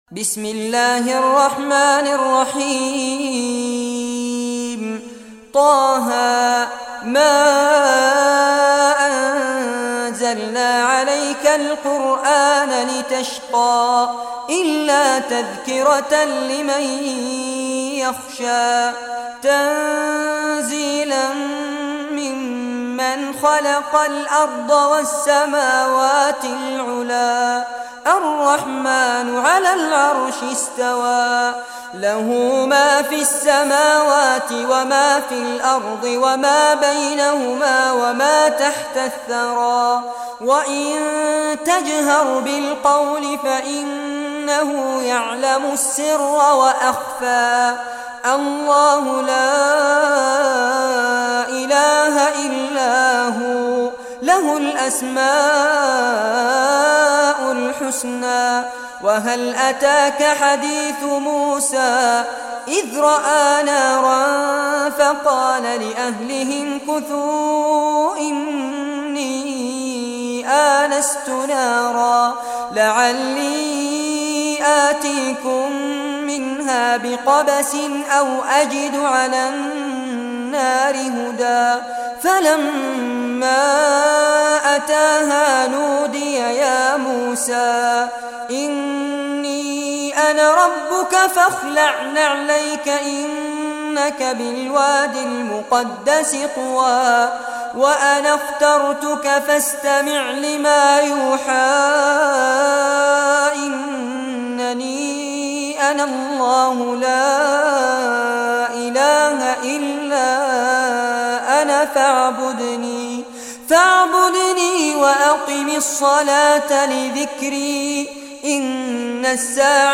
Surah Taha Recitation by Sheikh Fares Abbad
Surah Taha, listen or play online mp3 tilawat / recitation in Arabic in the beautiful voice of Sheikh Fares Abbad.